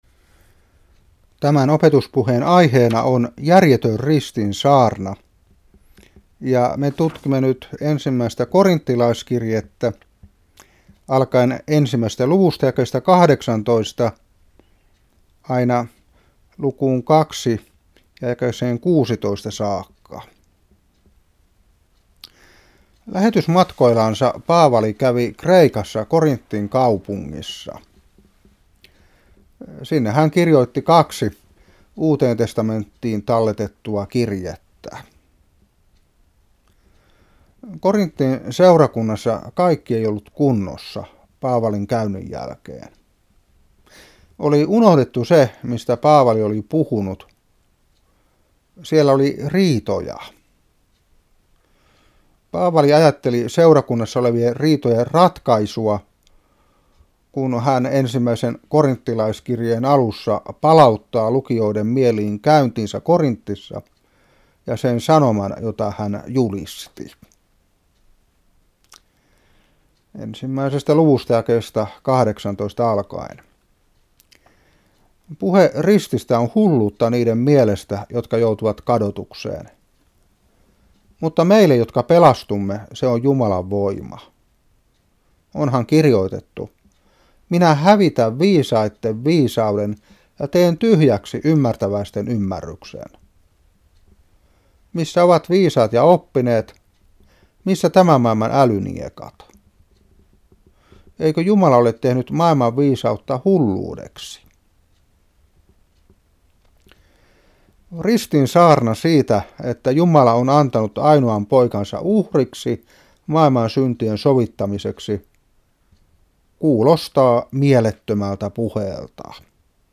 Opetuspuhe 2008-4. 1.Kor.1:18 - 2:16.